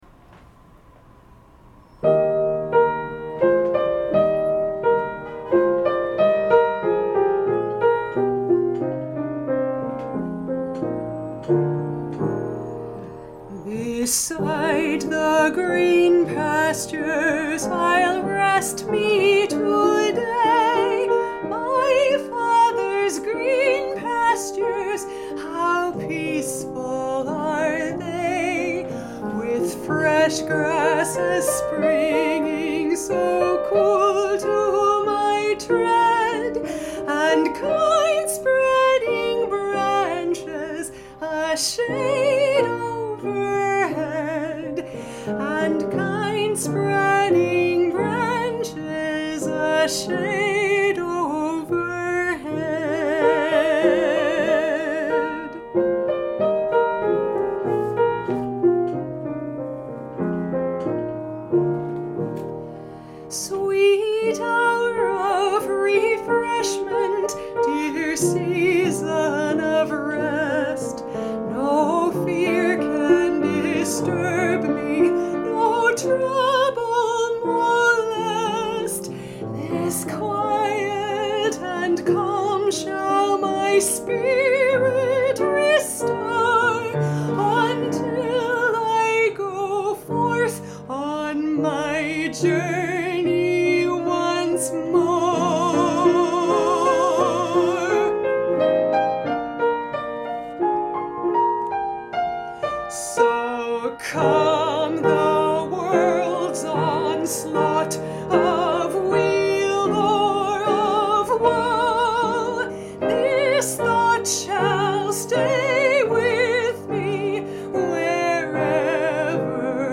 Latest Solos
+Green Pastures - Taylor/Sanderson 2/12/25 Eagleton